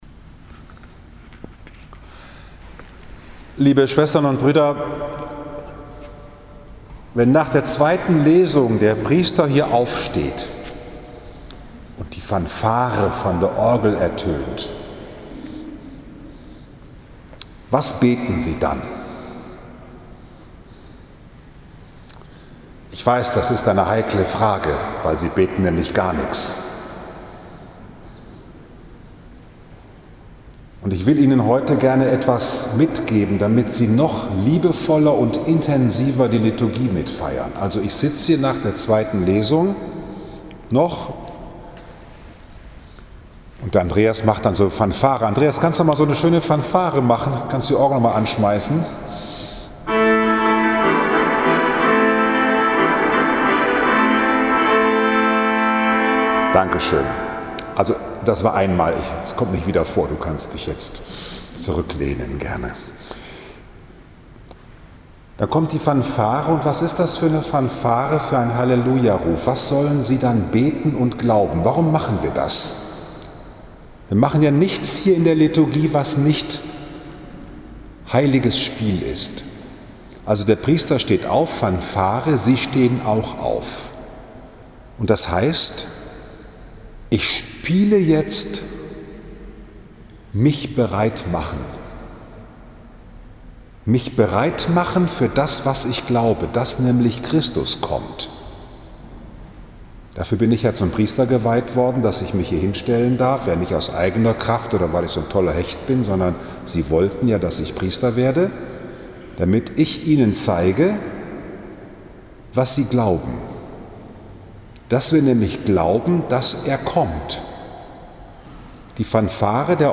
7. November 2020, 17 Uhr, Liebfrauenkirche Frankfurt am Main, 32. So.i.J. Lesejahr A